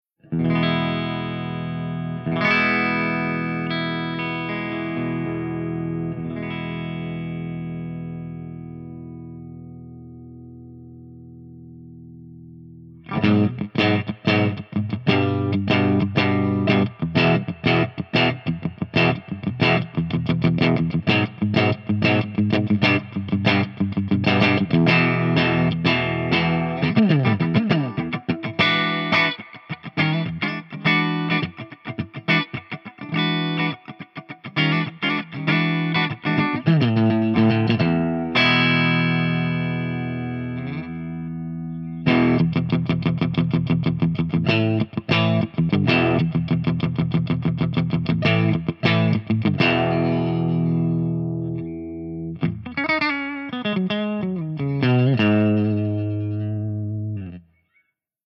168_ENGLSP_CH1CLEANBRIGHT+REV_V30_SC